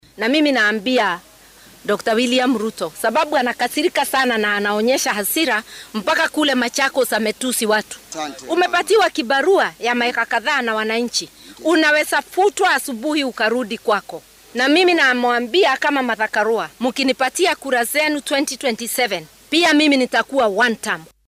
Iyada oo ka hadashay dowlad deegaanka Tana River Karua ayaa waxaa ay ku eedeysay dowladda sal ballaaran iyo hoggaamiyeyaasheeda inay dayaceen danta dadweynaha iyagoo u hiilinaya faa’iido siyaasadeed halka muwaadiniinta ay wajahayaan caqabado aan la xallin.